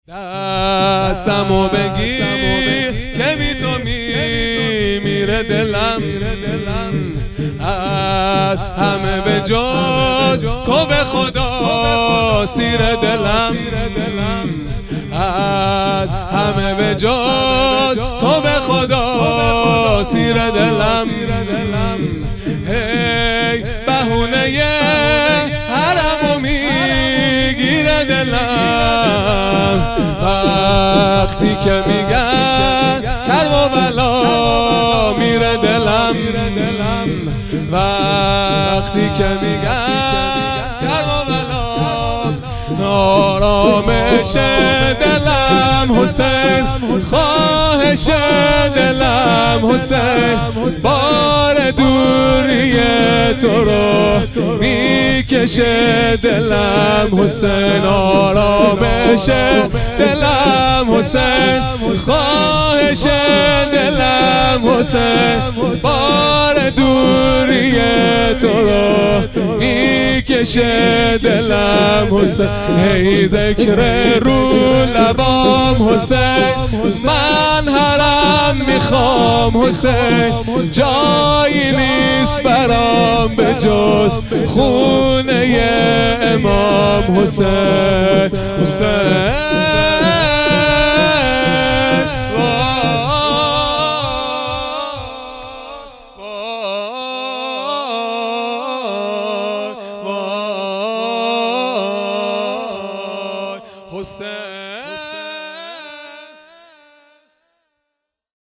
شور | دستمو بگیر که بی تو میمیره دلم | چهارشنبه ۲۰ مرداد ۱۴۰۰
دهه اول محرم الحرام ۱۴۴۳ | شب سوم | چهارشنبه ۲۰ مرداد ۱۴۰۰